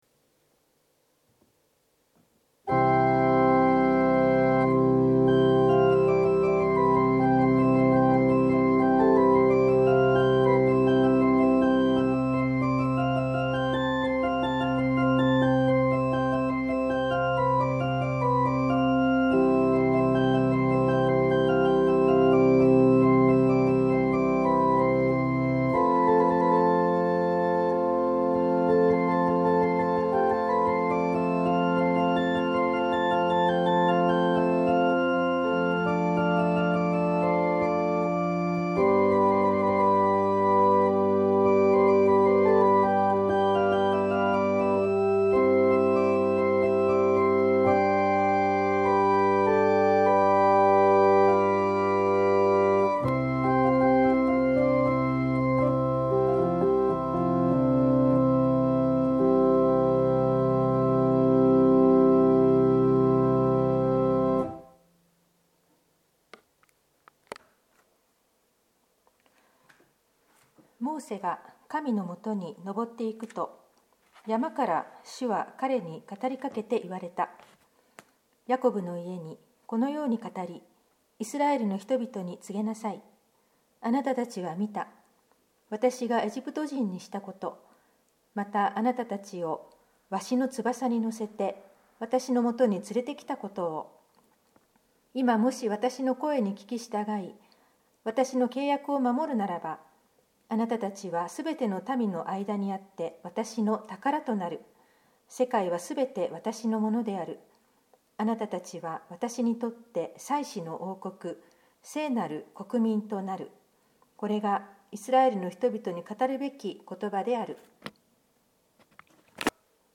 聖日礼拝のご案内（復活節第六主日礼拝） – 日本基督教団 花小金井教会
説教「天の国の鍵」 マタイによる福音書１６：１３～２０